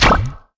launch_shield_impact2.wav